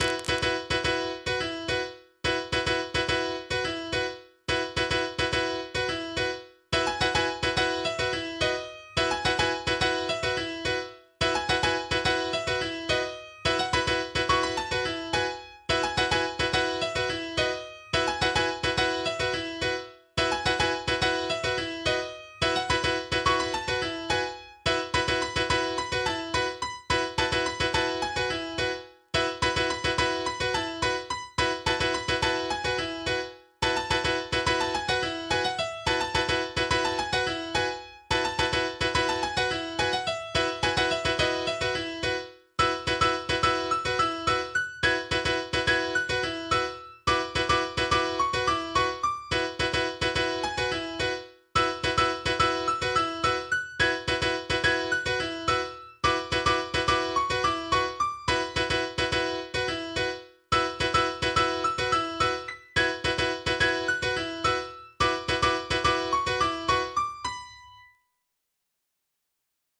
MIDI 6.28 KB MP3 (Converted) 1.07 MB MIDI-XML Sheet Music